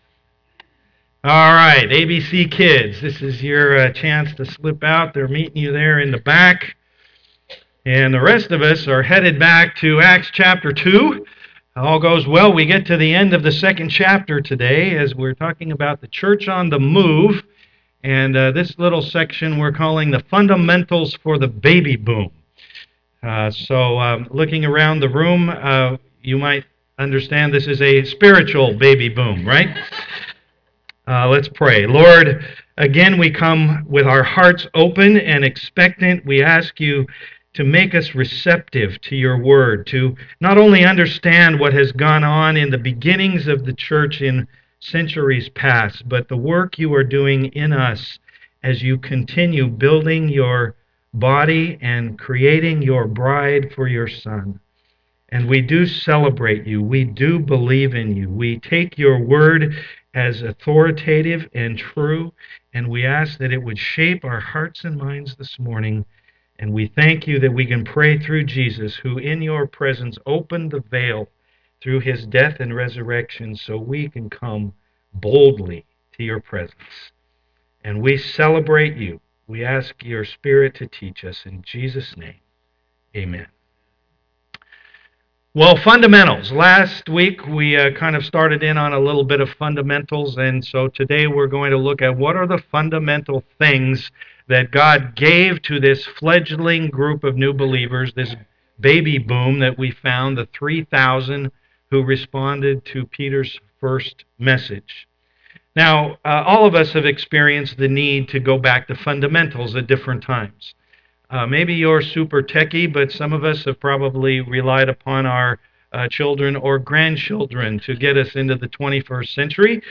Passage: Acts 2:42-47 Service Type: am worship